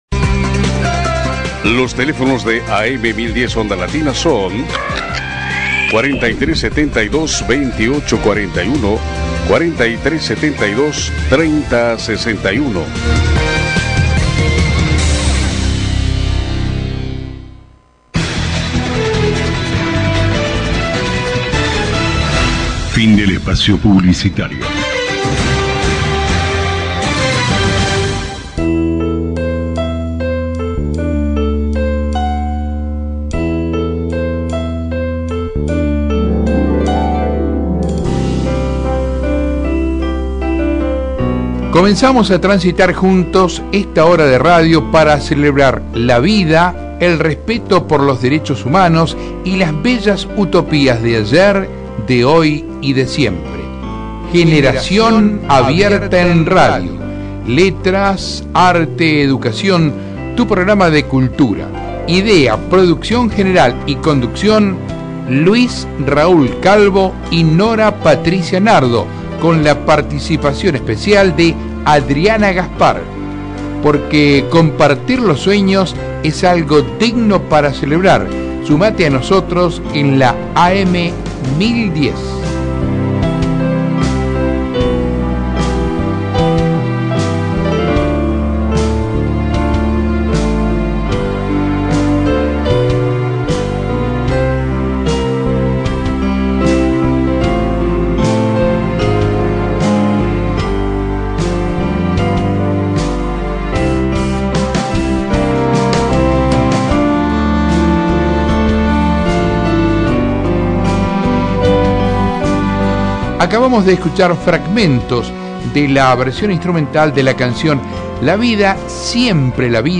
Por la Radio AM 1010 "Onda Latina", Buenos Aires, Argentina.
Diálogo en vivo con el actor Aldo Pastur